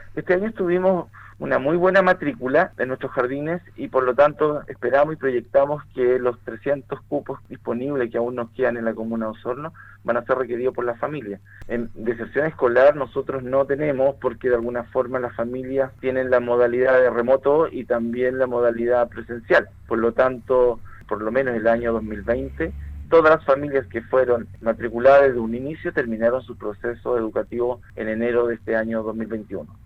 En entrevista con Radio Sago, el director regional de la Junji Los Lagos Eduardo Hernández, se refirió al retorno a los recintos preescolares en comunas que ya no se encuentran en un estado de cuarentena.